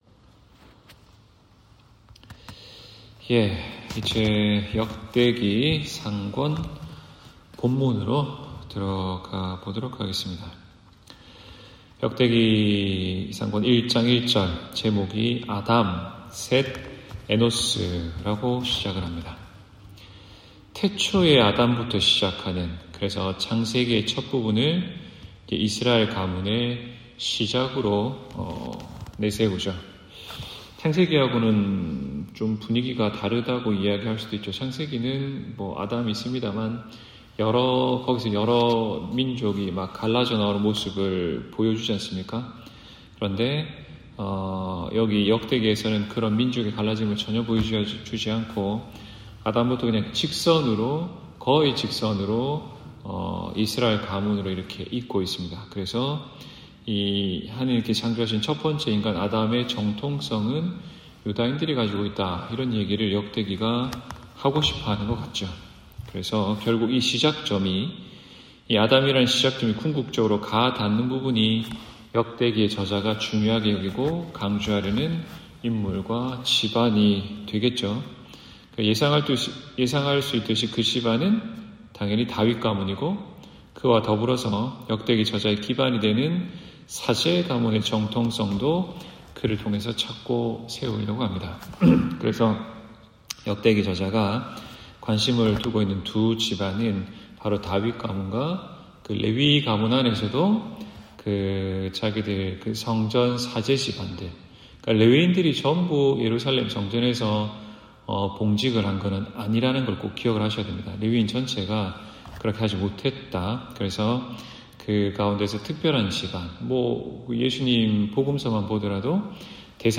성서공부130-역대기상 (2024년 10월30일 수요일)